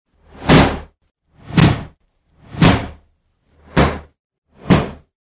Звуки удара по голове
На этой странице собраны различные звуки ударов по голове – от мультяшных до максимально реалистичных.